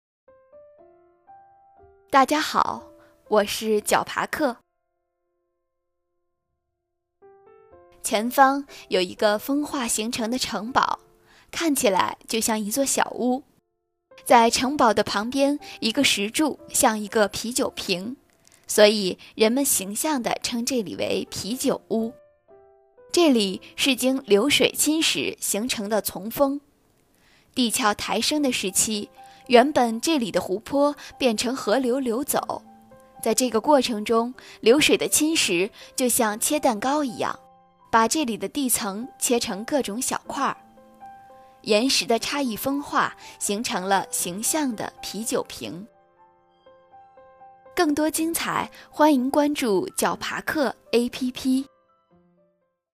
啤酒屋（峰丛）----- 牛条龙 解说词: 前方有一个风化形成的城堡，看起来就像一座小屋，在城堡旁边一个石柱像一个啤酒瓶。